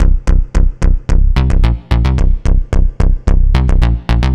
AM_OB-Bass_110-C.wav